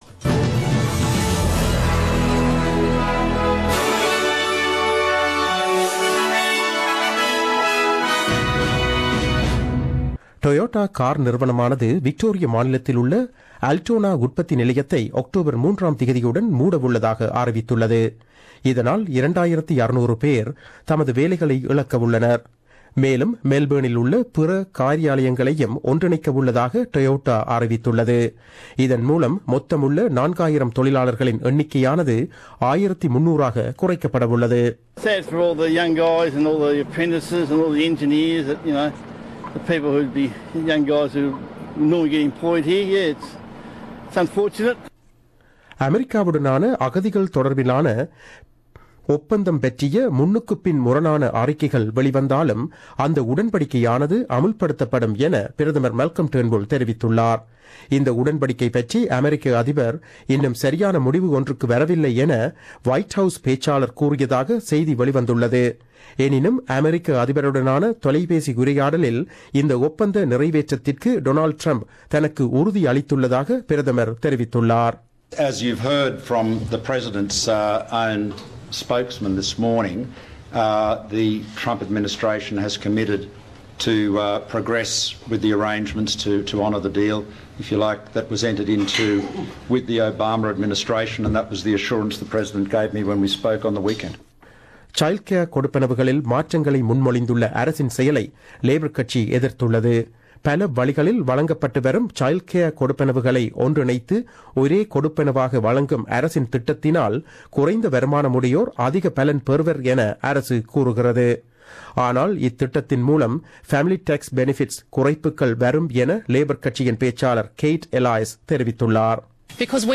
The news bulletin aired on 01 February 2017 at 8pm.